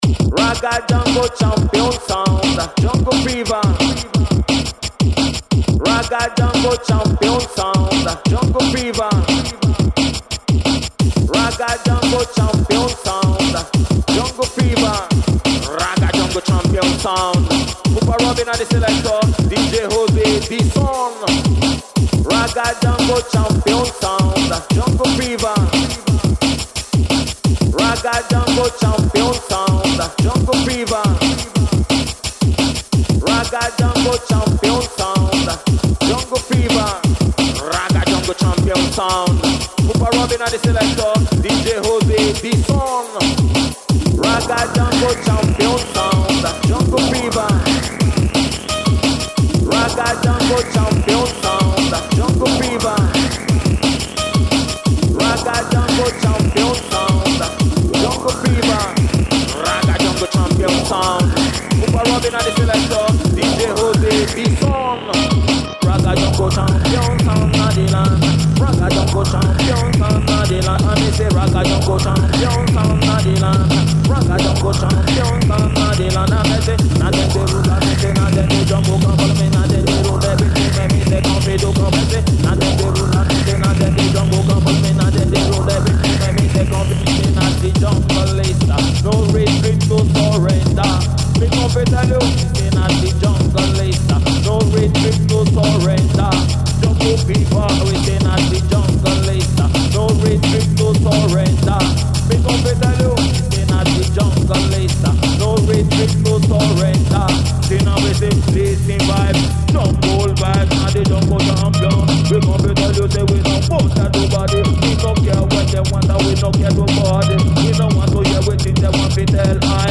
koncertna dvorana